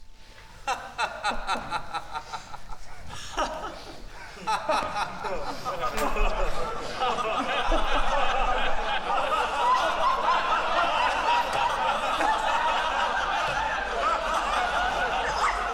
BarbiereDeSevilia_public_HAHAHA
Crowd haha Laugh sound effect free sound royalty free Funny